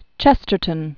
(chĕstər-tən), Gilbert Keith 1874-1936.